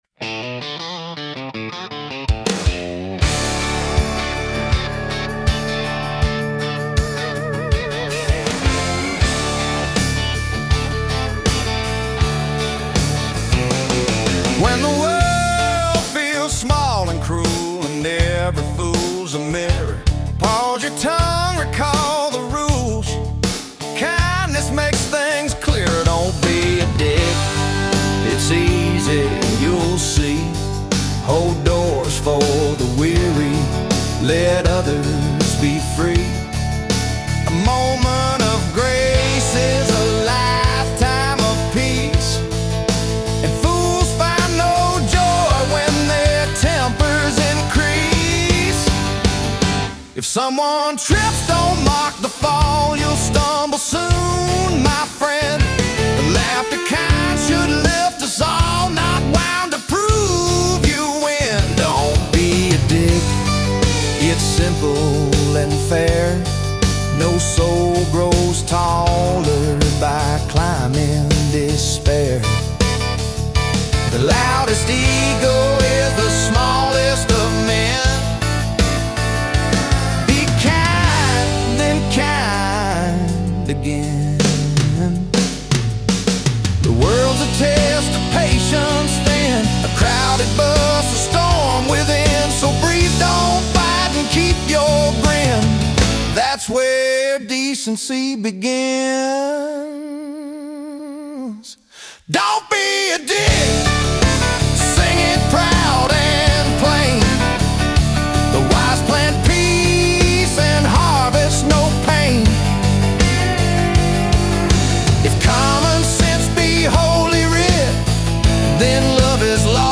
Listen to a specific hymn recording from the Church of Common Sense.
Cinematic